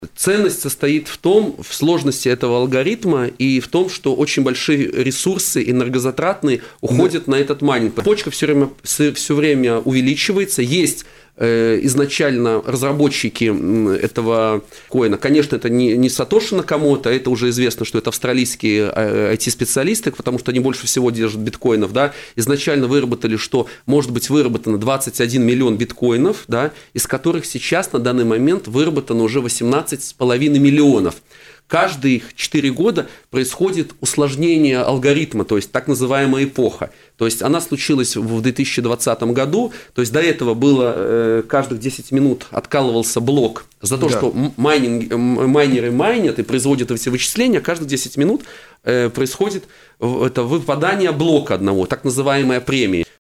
в эфире программы «Вокруг финансов» на радио Baltkom